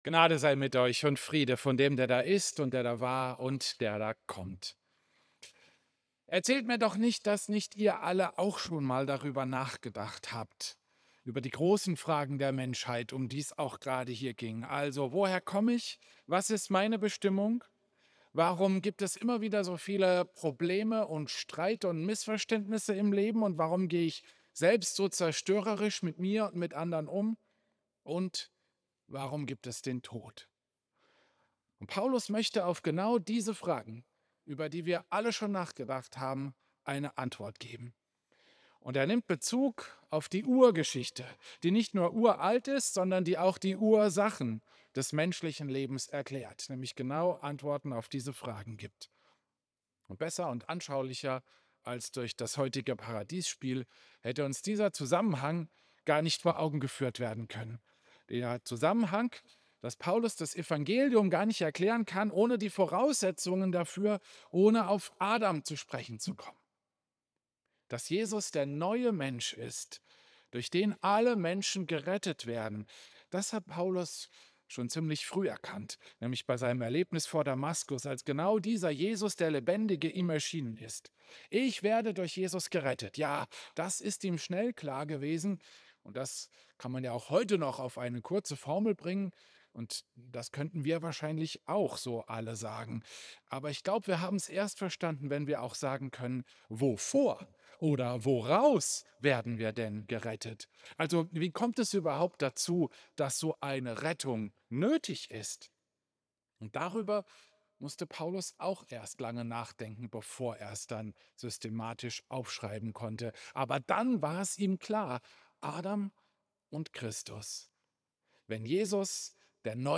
Klosterkirche Volkenroda, 14. Dezember 2025